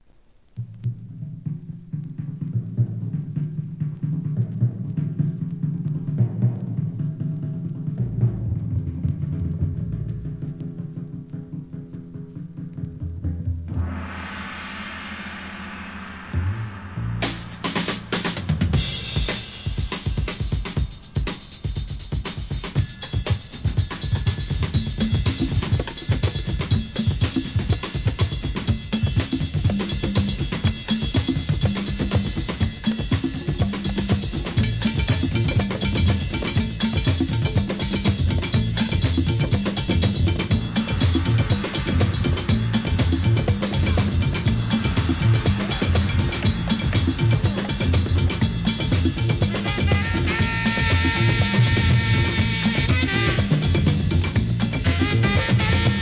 HIP HOP/R&B
アカペラ、ブレイクなど、DJ TOOLがB/Wに収録!!